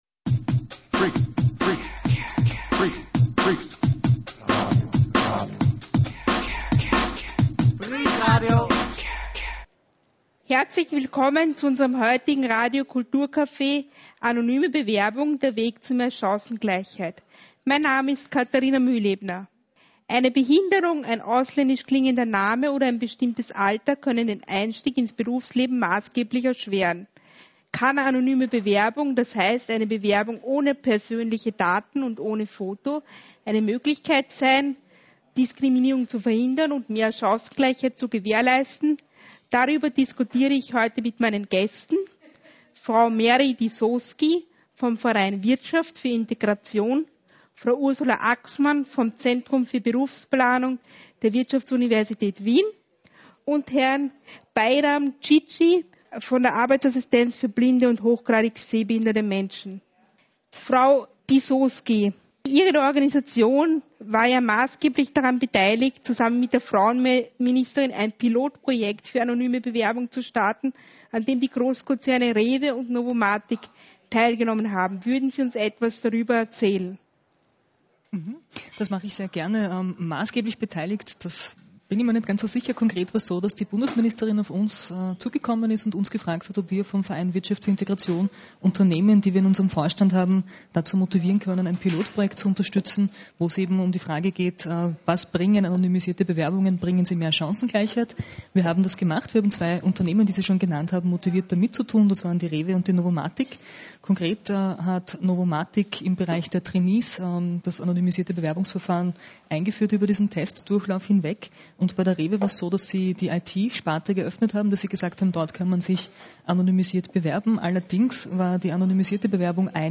Diese Sendung kostenlos nachhören.